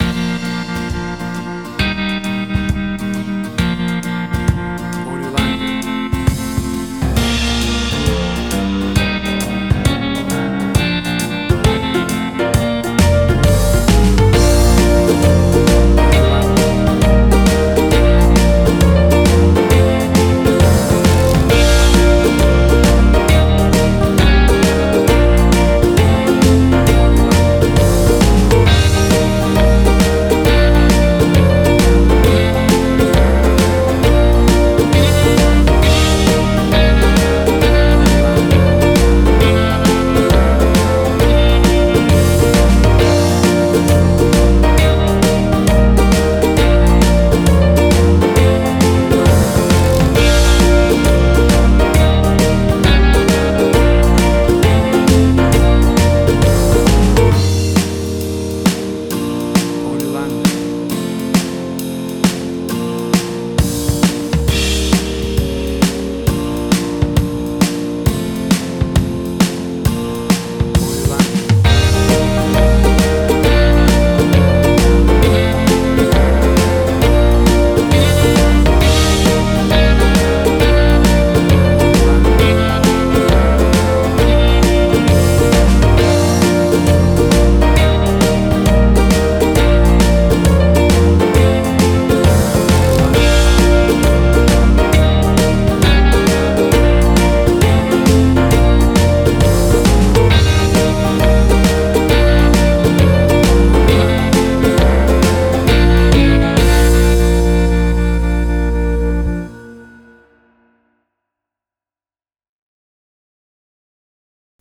A cool and bouncy piece of Country Folk style music.
WAV Sample Rate: 16-Bit stereo, 44.1 kHz
Tempo (BPM): 133